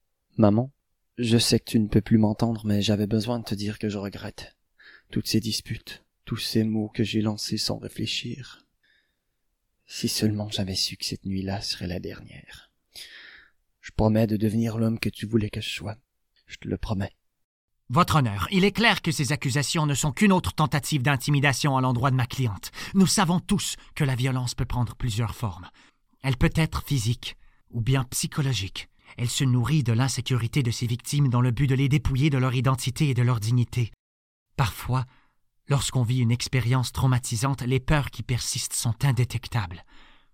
Français normatif - FR